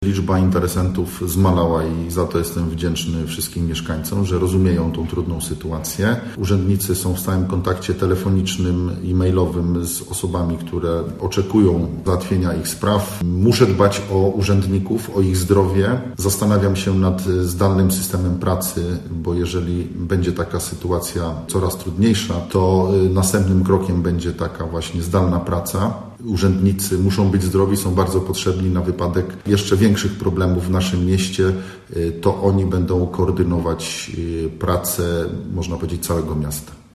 Mówi burmistrz Sandomierza Marcin Marzec: